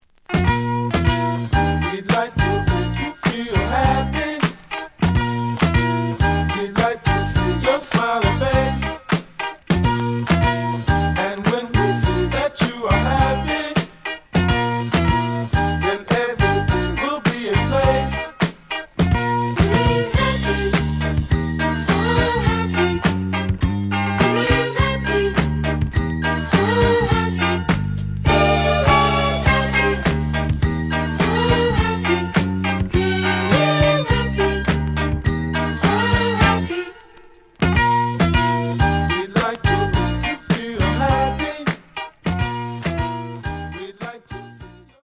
The audio sample is recorded from the actual item.
●Genre: Disco
Some cloudy on B side.